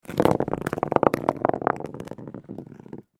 Metal-ball-rolling.mp3